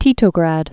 (tētō-grăd, -gräd)